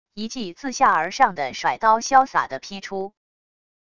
一记自下而上的甩刀潇洒的劈出wav音频